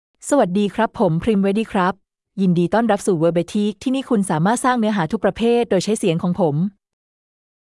FemaleThai (Thailand)
Voice sample
Female
Premwadee delivers clear pronunciation with authentic Thailand Thai intonation, making your content sound professionally produced.